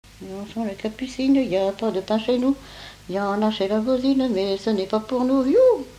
Mémoires et Patrimoines vivants - RaddO est une base de données d'archives iconographiques et sonores.
Enfantines - rondes et jeux
Pièce musicale inédite